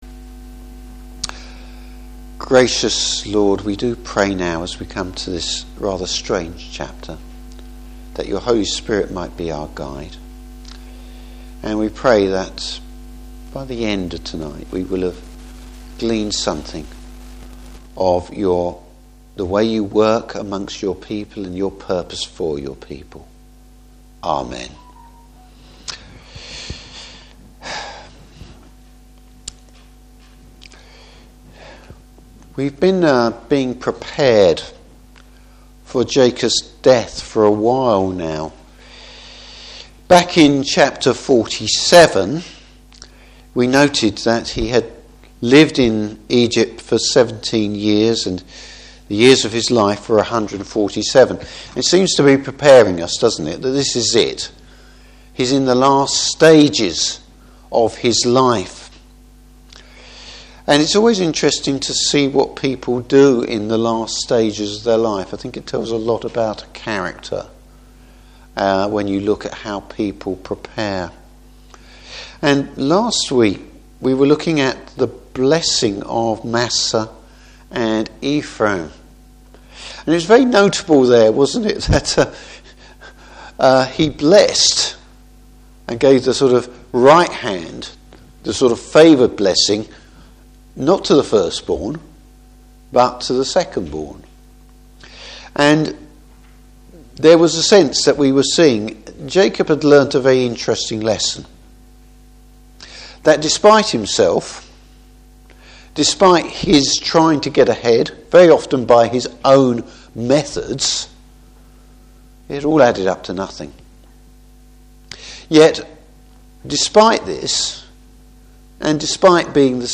Service Type: Evening Service Jacob’s prophecy concerning the future of Israel.